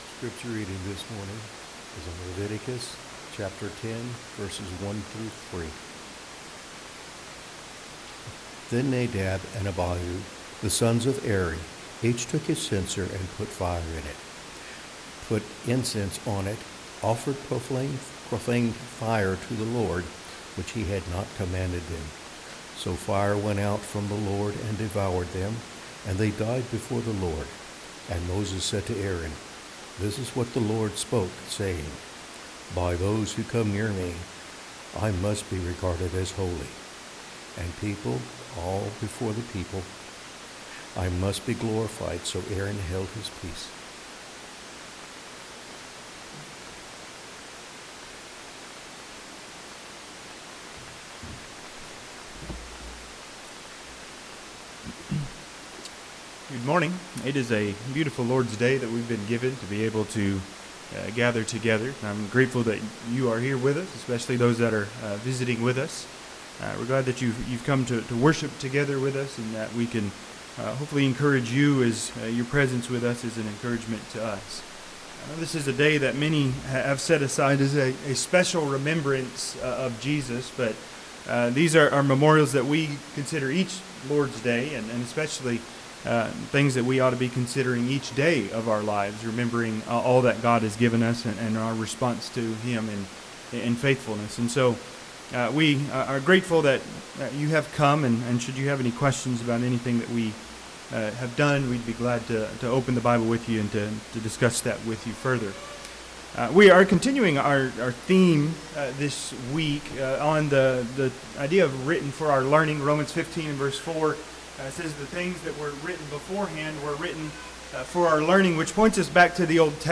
Leviticus 10:1-3 Service Type: Sunday AM Topics